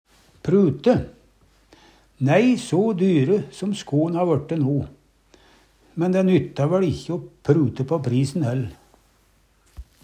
prute - Numedalsmål (en-US)